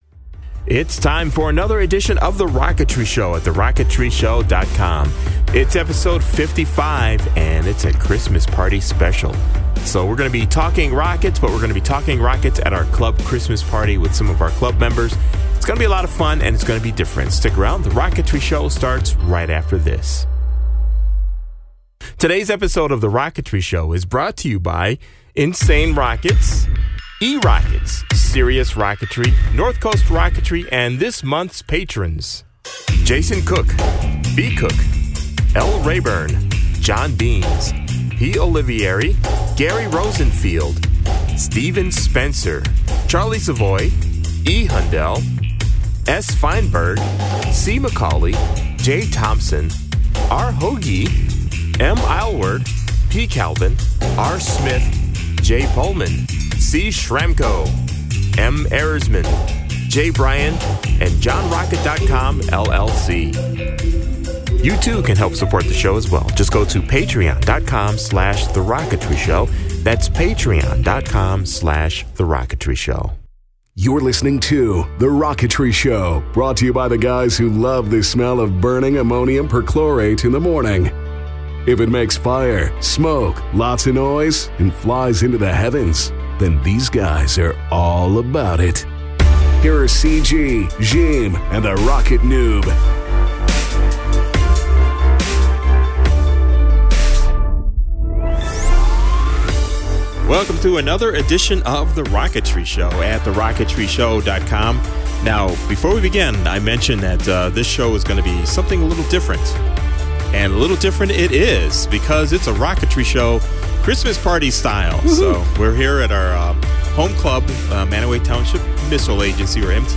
The team goes on the road, and set up at their club Christmas party and record a show.